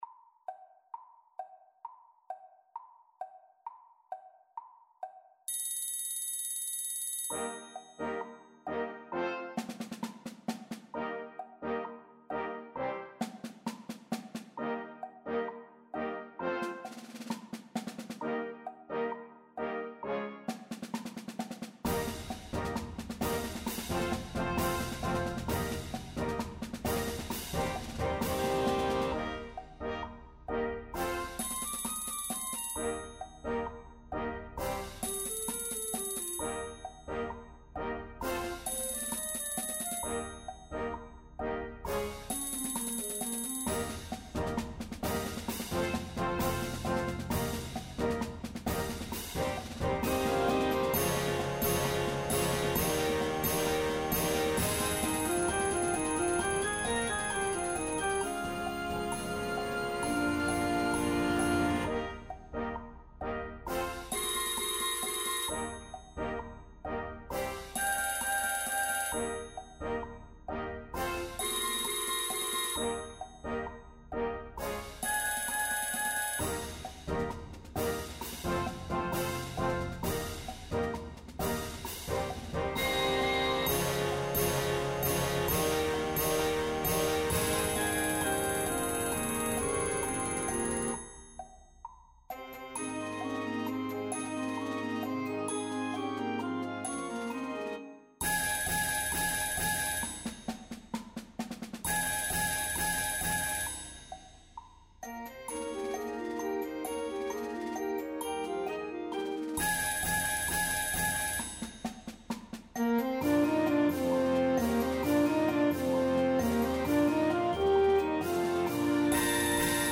The constant ticking of some clock somewhere in your house.